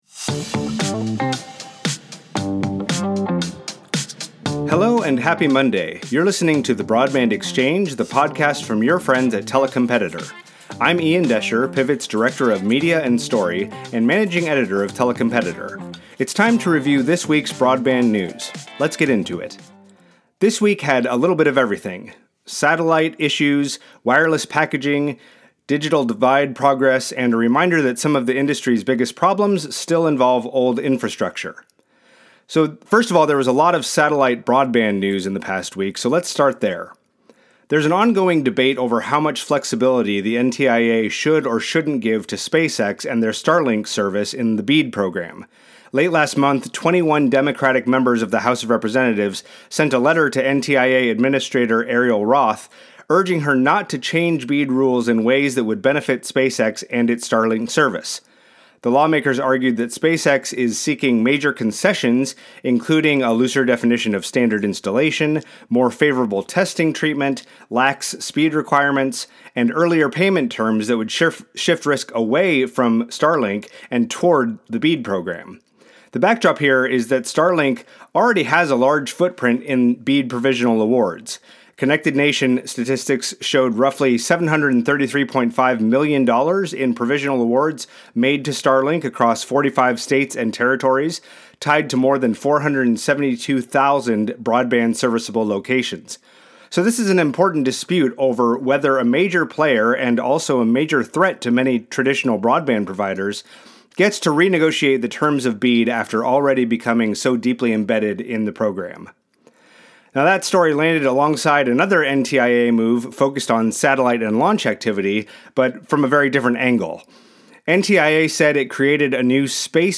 The Broadband Exchange features industry experts sharing practical insights and perspectives you can use as you work to bring broadband to communities within North America.